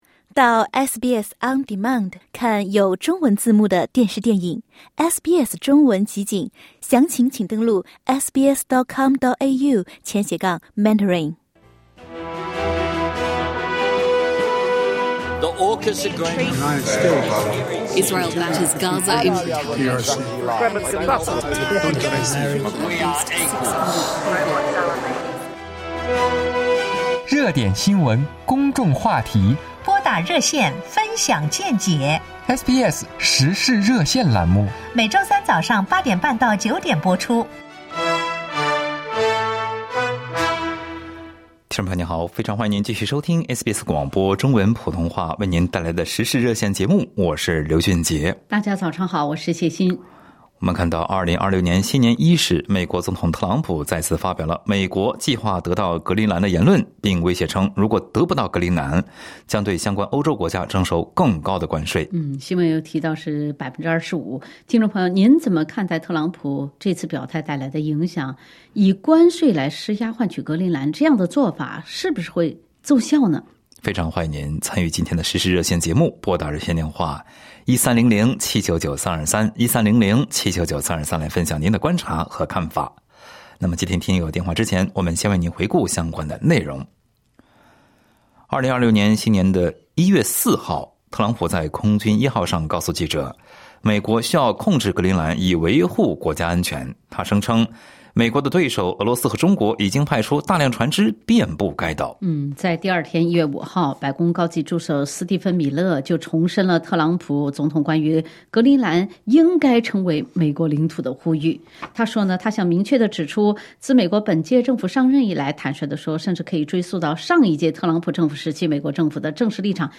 在本期《时事热线》节目中，听友们分享了各自的看法。